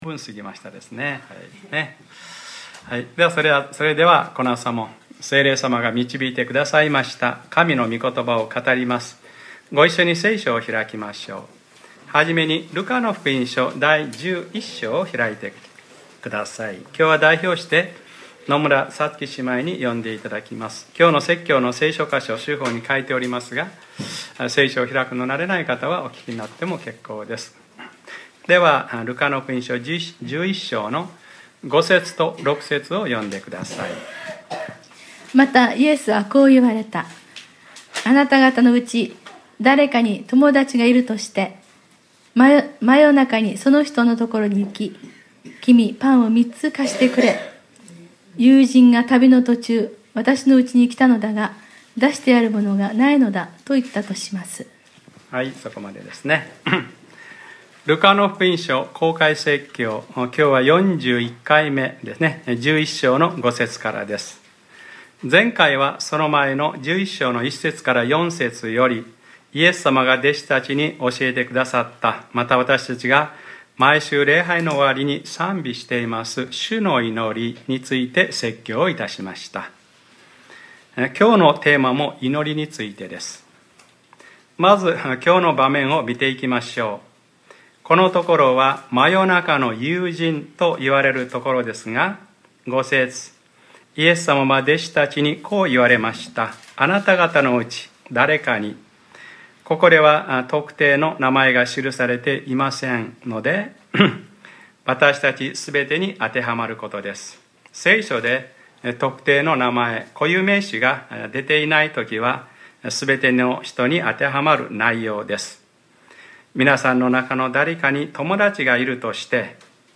2014年7月 6日（日）礼拝説教 『ルカｰ４１：あきらめないで求め祈りなさい』